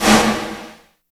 44 VERB SN-L.wav